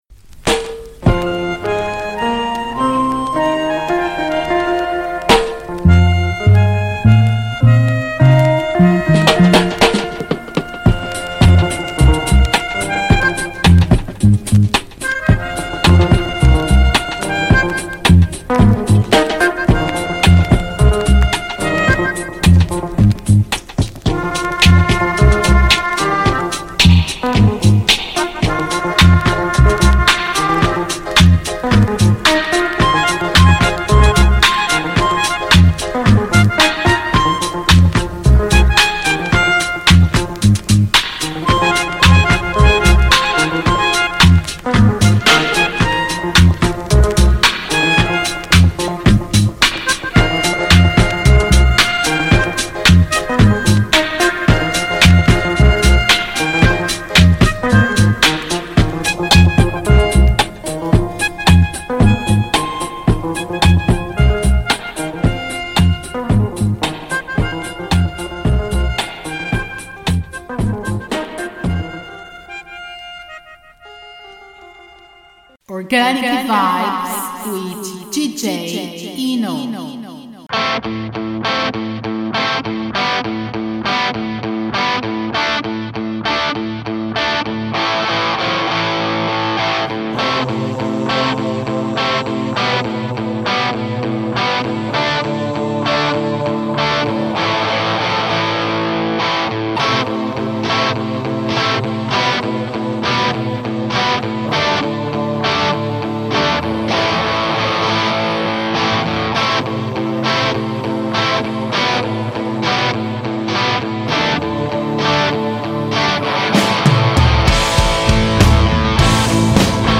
New show with a lot of new music(K) plus some older ones, to coincide with the schools reopening after the summer holidays.